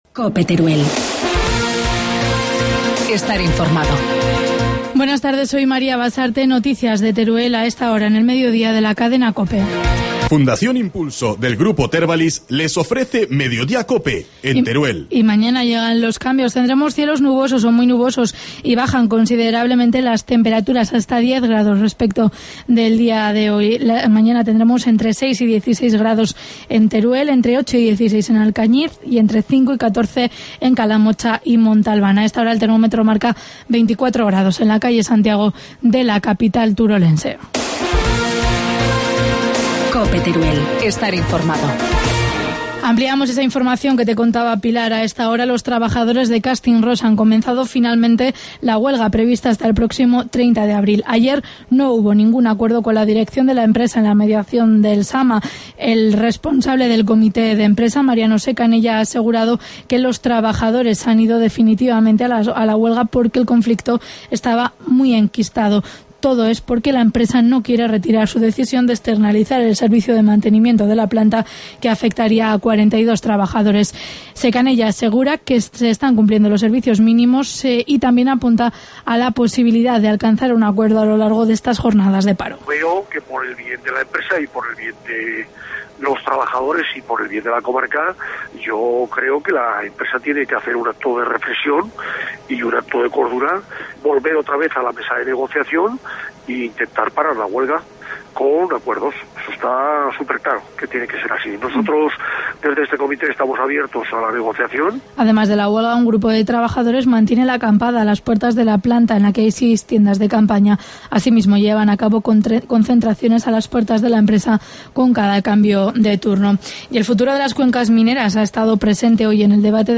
Informativo mediodía, jueves 18 de abril